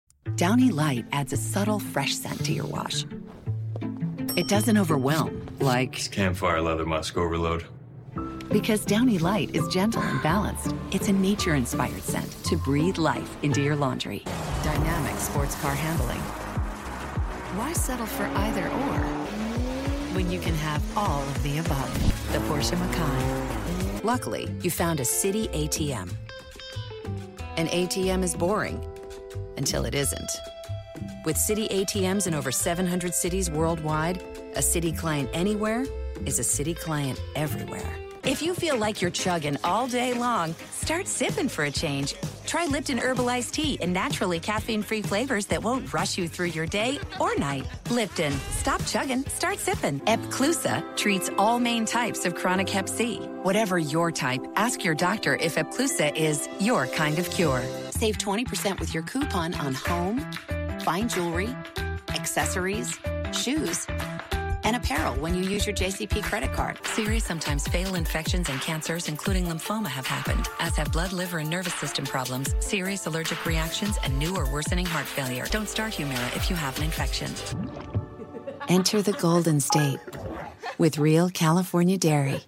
new york : voiceover : animation